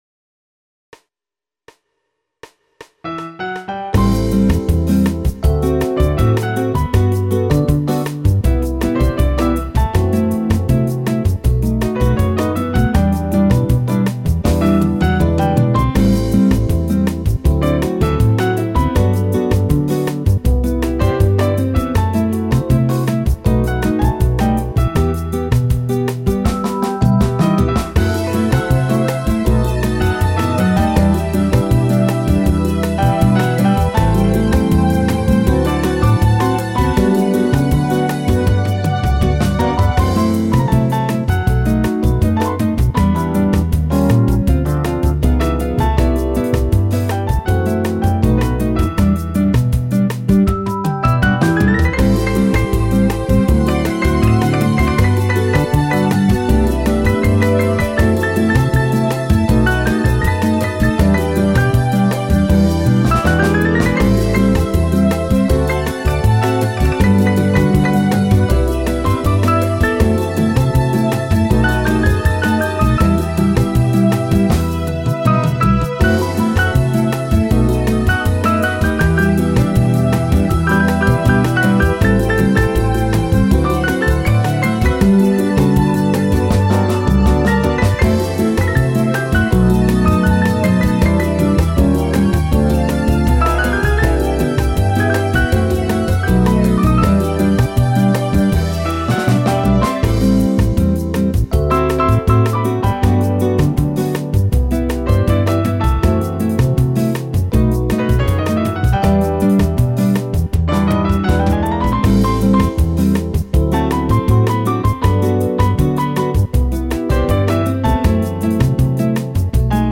3/   Tanečné melódie
upravené pre hru na piano